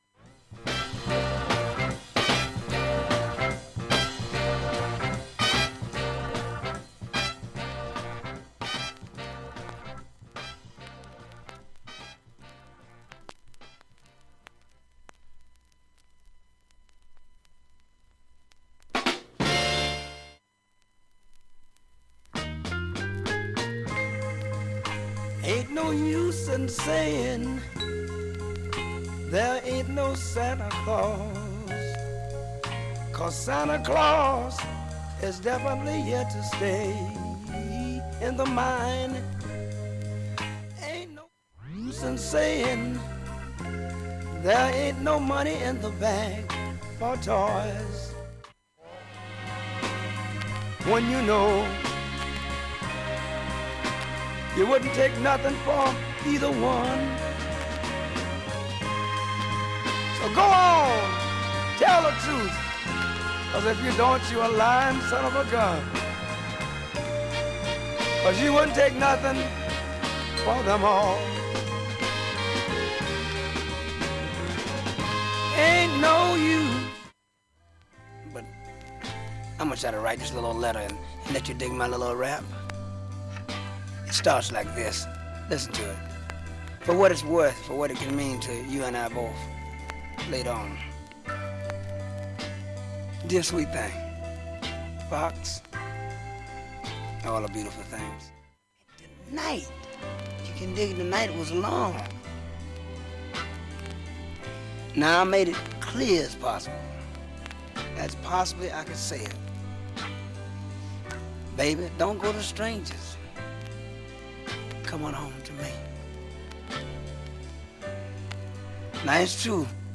音質良好全曲試聴済み。
勇ましいファンクを基礎に置いた
荒々しいＲ＆Ｂクリスマスアルバム
真逆な、爽快にファンキーな